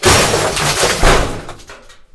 anima_fragment_death.ogg